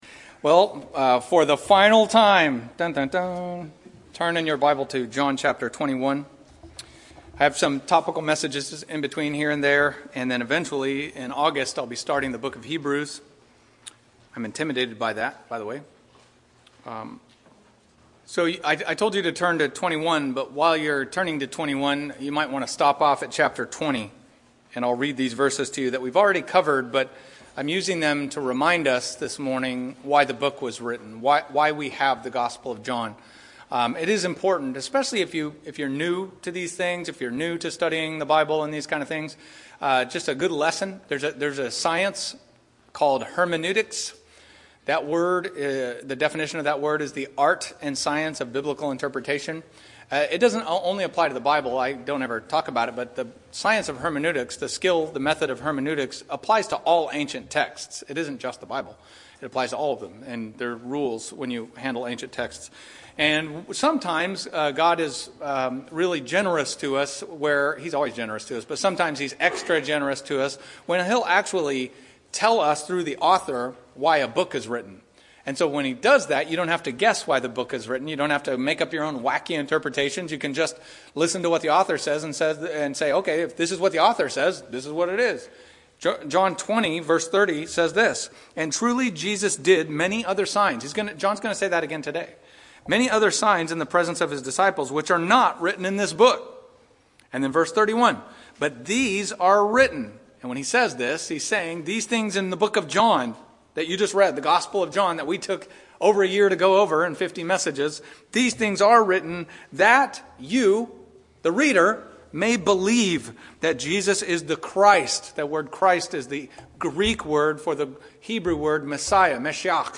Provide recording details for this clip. Listen to sermons and Bible-based messages from Sovereign Grace Baptist Church in Modesto, CA. Audio, video, and notes.